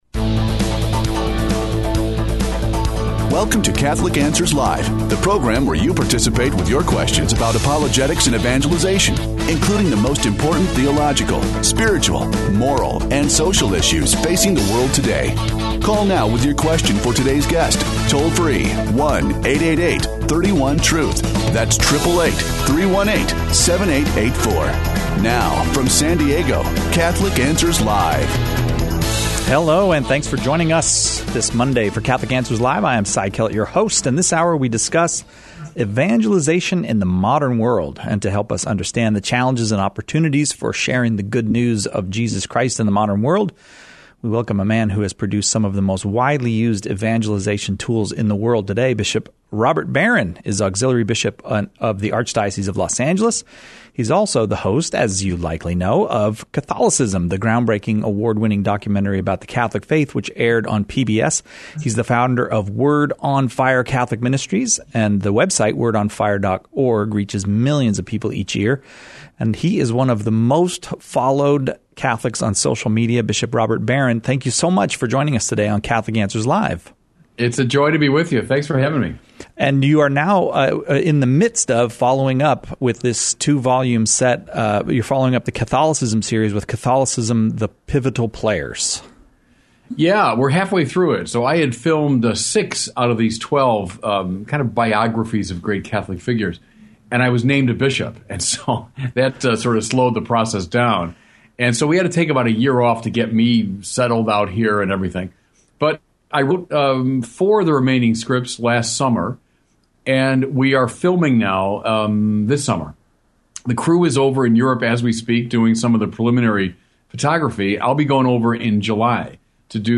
One of the most respected evangelizers in the world today stops by to talk with us about evangelizing in the modern world.